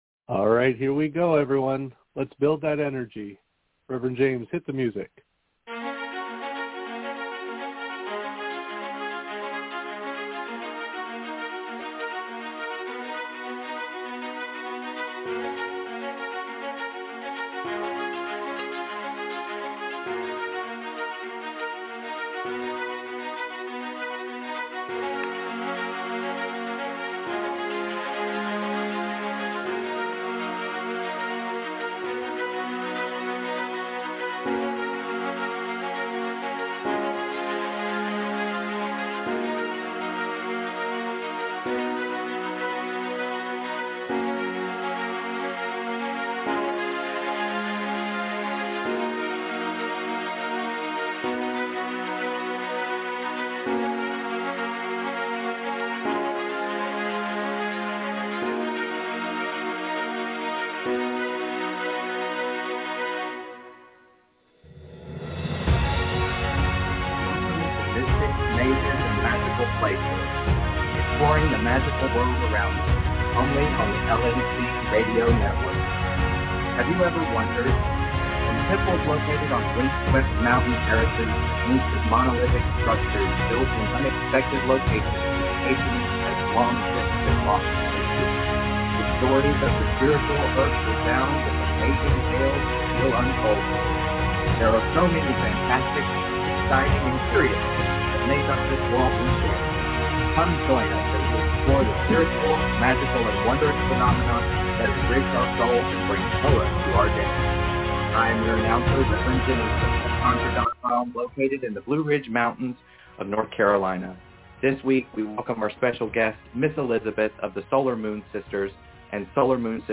We begin this show with an interview of our guest followed by a discussion of the use of Tarot as a tool for shadow work.